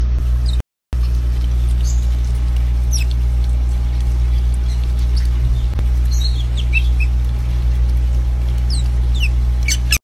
Just 2 Otters eating Shrimp sound effects free download